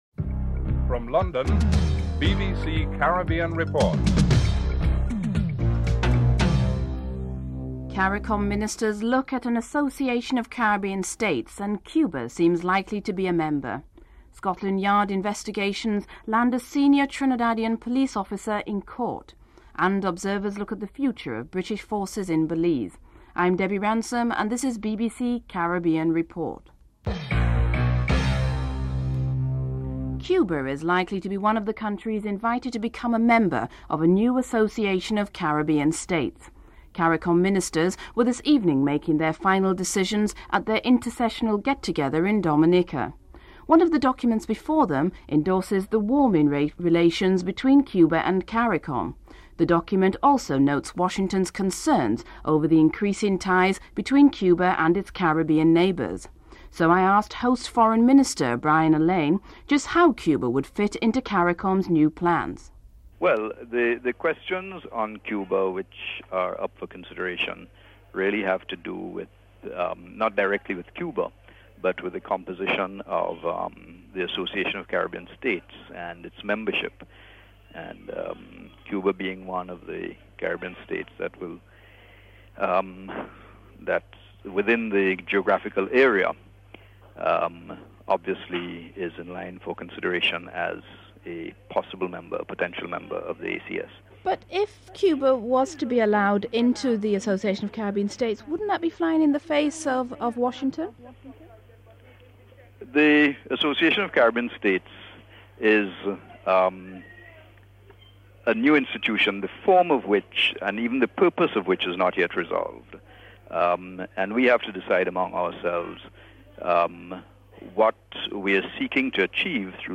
4. A Radio advertisement for Jamaica’s two major parties – the People’s National Party and the Jamaica Labour Party is issued to promote the two parties (09:27- 12:01).